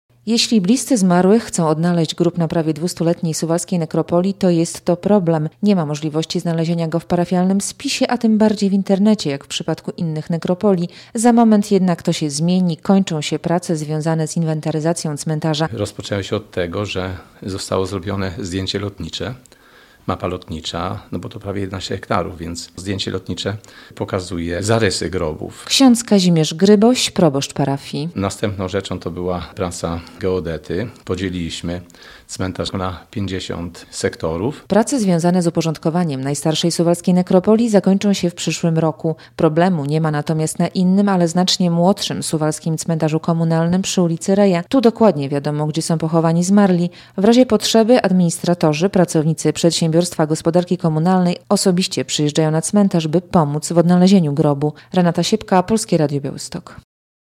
Inwentaryzacja cmentarza przy ulicy Bakałarzewskiej - relacja